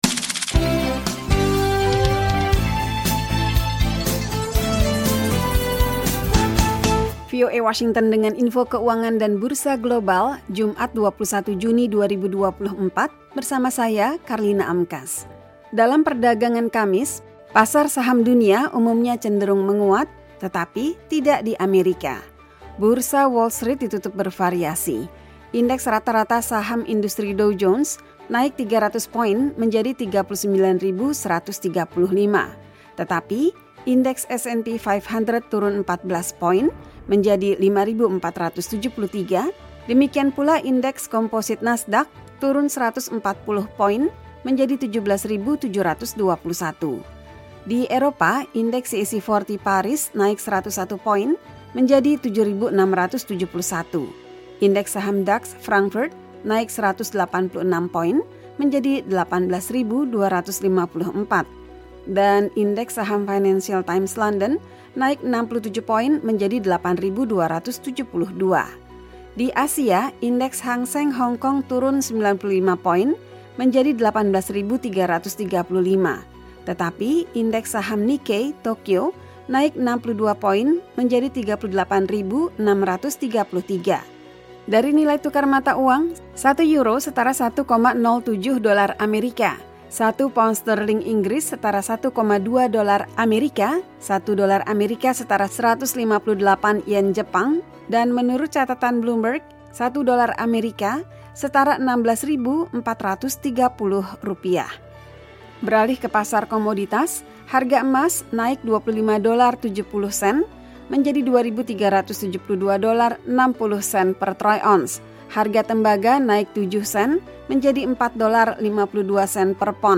Info Ekonomi